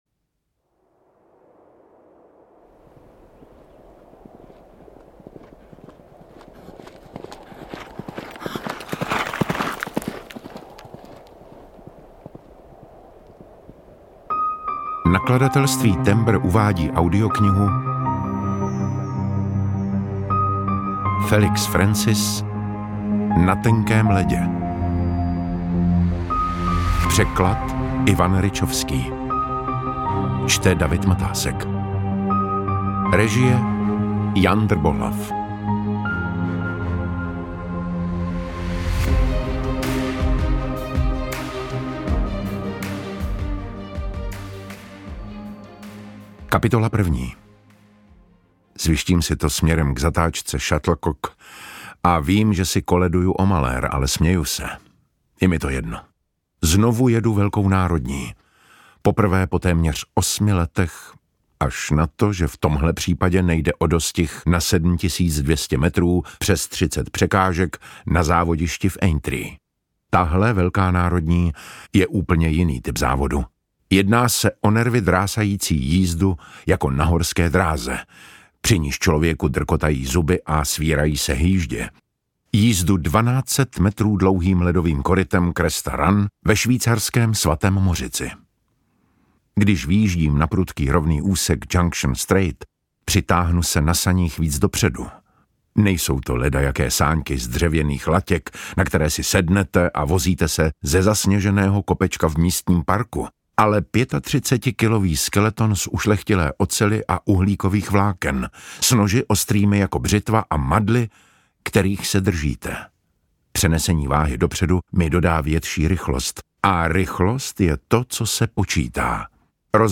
UKÁZKA Z KNIHY
Čte: David Matásek
audiokniha_na_tenkem_lede_ukazka.mp3